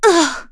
Laudia-Vox_Damage_kr_01.wav